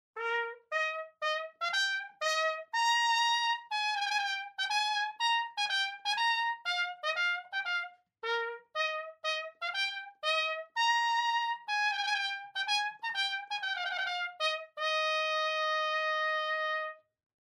Pikolo trompete.
Trompete
Trompetes mūzika